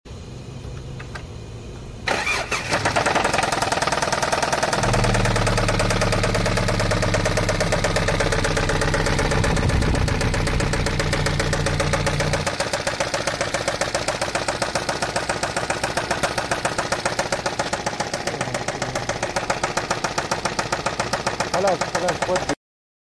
crankshaft sound sound effects free download